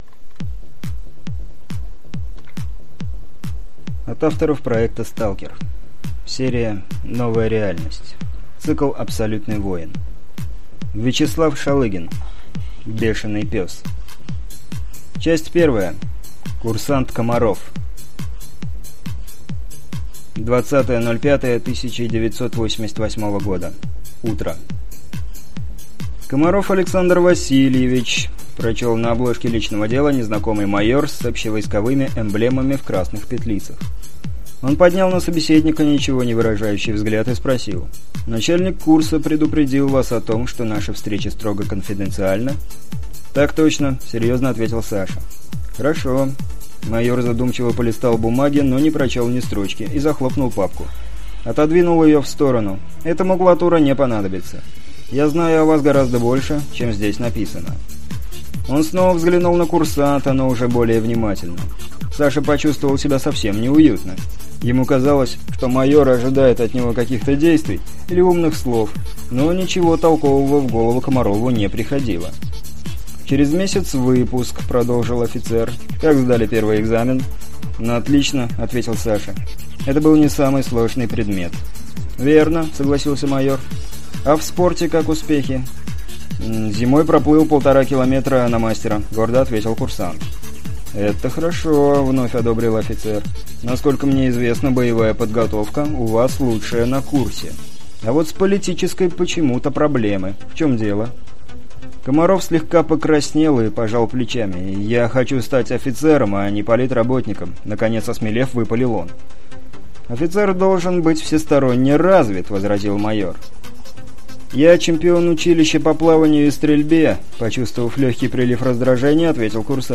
Аудиокнига Бешеный Пес | Библиотека аудиокниг